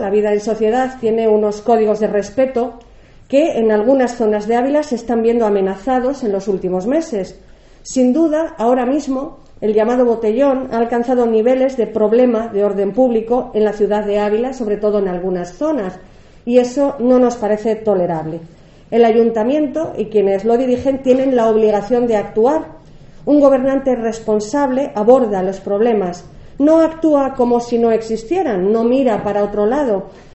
Sonsoles Sánchez-Reyes, portavoz PP. Botellón